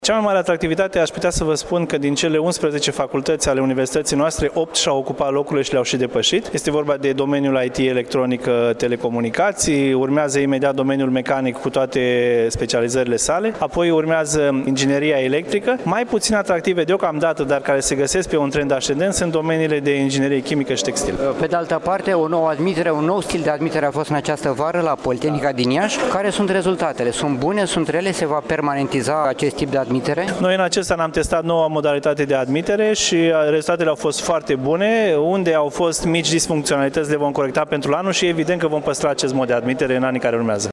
Astăzi, s-a deschis anul academic la Universitatea Tehnică Gheorghe Asachi din Iaşi, în prezența oficialităţilor locale şi judeţene.